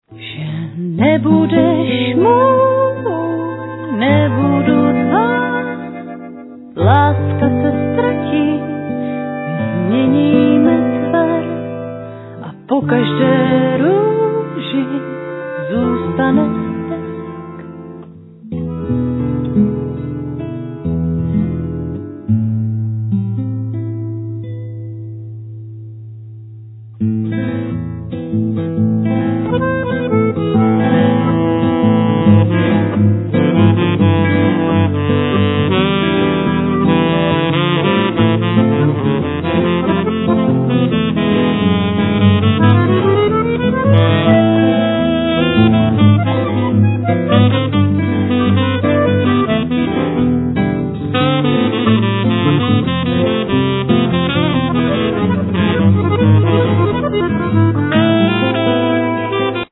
Vocals
Double bass
El.guitar
Drums
Ac.guitar
Accordion
Vocals, Flute
Violin, Viola
Saxophone